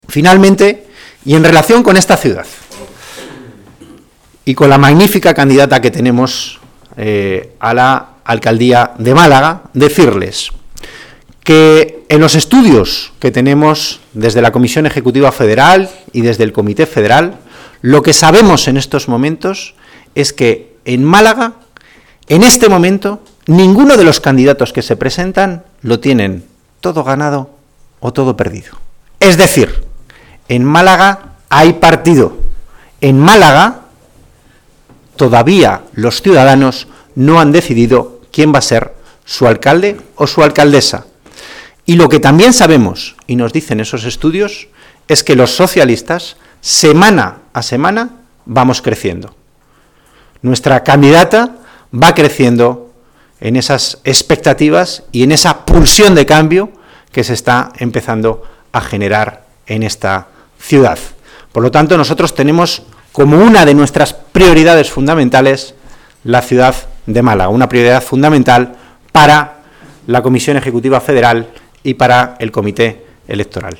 El secretario de Ciudades y Política Municipal del PSOE, Antonio Hernando, ha asegurado hoy en rueda de prensa, junto al secretario general del PSOE malagueño, Miguel Ángel Heredia, y a un grupo de candidatos socialistas de municipios de menos de 20.000 habitantes, que según estudios realizados desde la Comisión Ejecutiva Federal y del Comité Electoral socialista "en estos momentos en Málaga capital ninguno de los candidatos que se presentan lo tienen todo ganado o todo perdido".